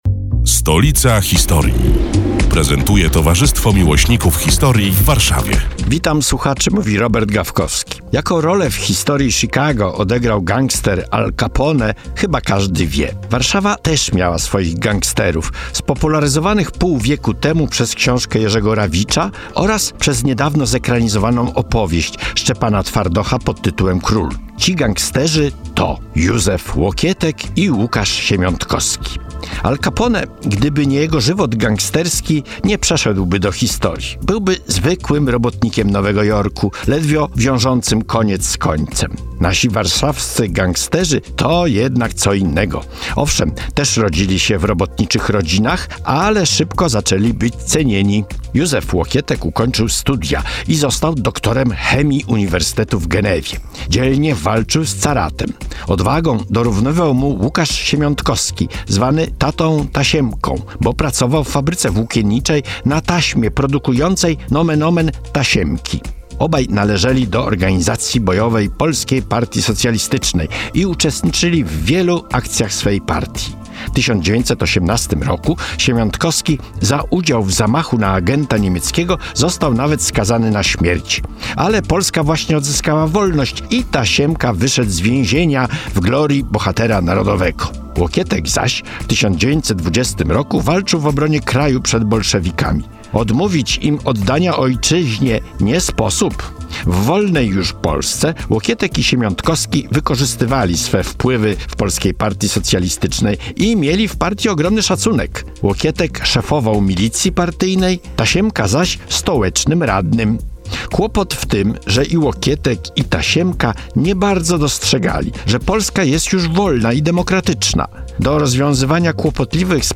82. felieton pod wspólną nazwą: Stolica historii. Przedstawiają członkowie Towarzystwa Miłośników Historii w Warszawie, które są już od prawie dwóch lat emitowane w każdą sobotę, w nieco skróconej wersji, w Radiu Kolor, rozpoczyna kolejny sezon historycznych gawęd.